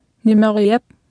Speech synthesis Martha to computer or mobile phone
Speech Synthesis Martha